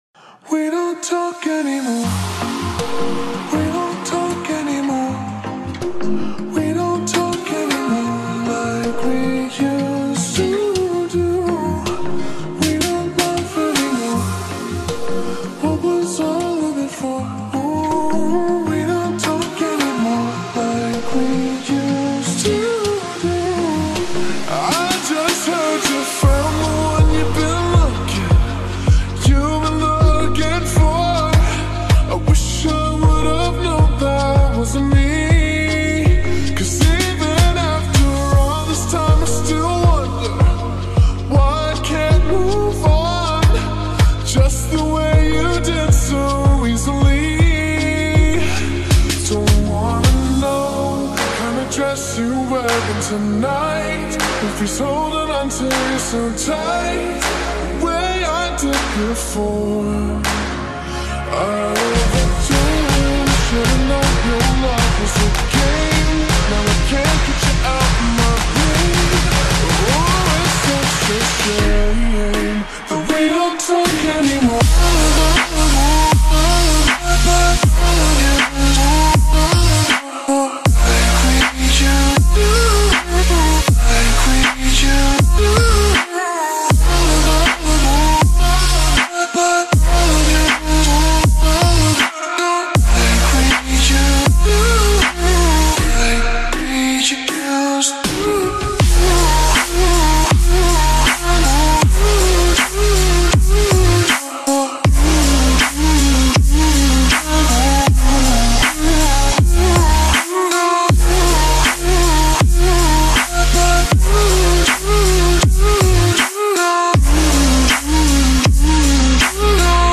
это современная поп-баллада